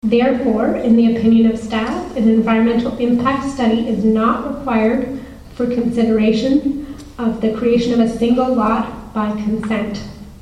The matter was addressed at a Public Meeting of the Committee at the Nick Smith Centre in Arnprior February 5th.